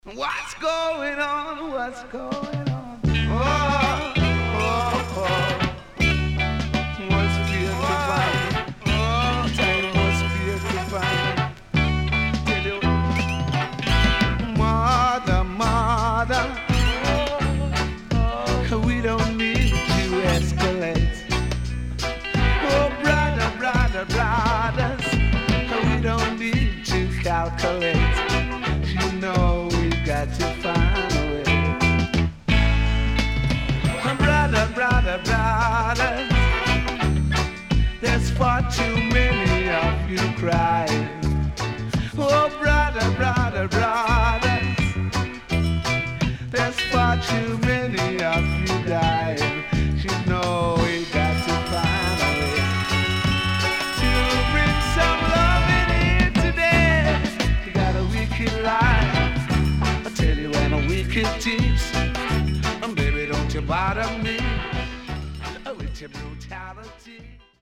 HOME > LP [VINTAGE]  >  70’s DEEJAY  >  RECOMMEND 70's